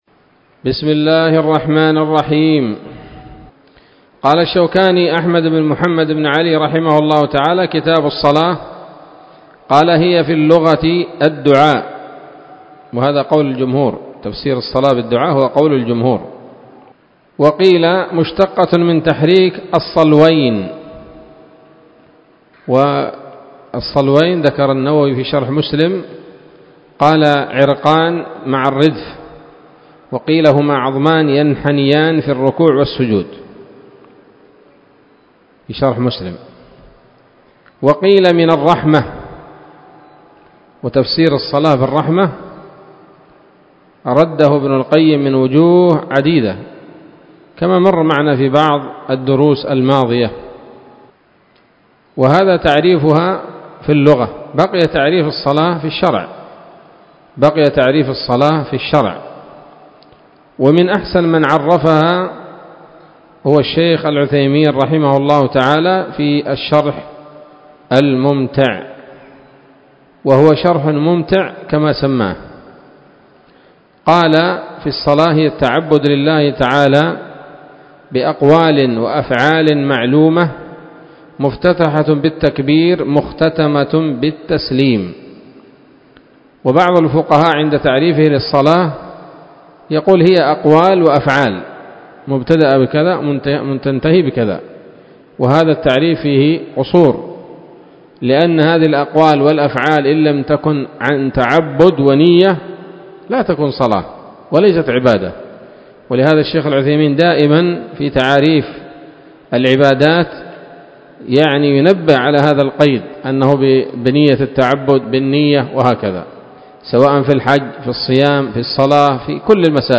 الدرس الأول من كتاب الصلاة من السموط الذهبية الحاوية للدرر البهية